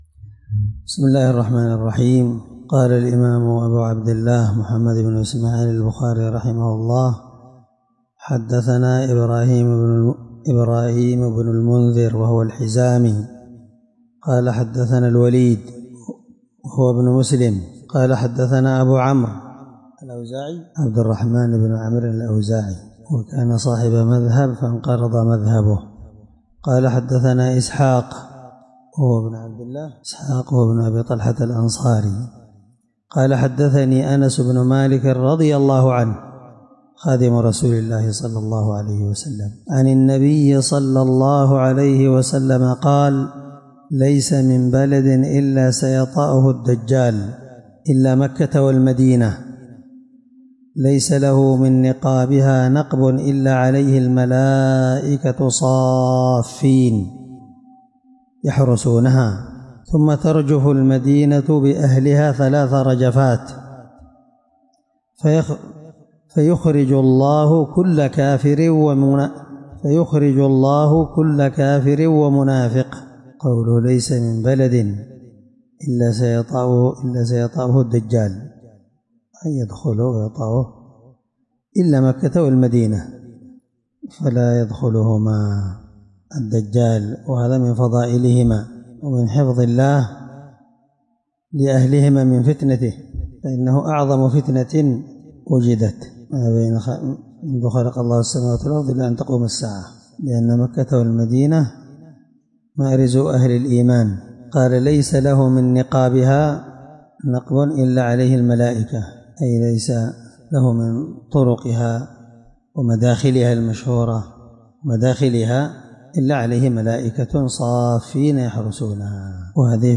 الدرس11من شرح كتاب فضائل المدينة حديث رقم(1881 )من صحيح البخاري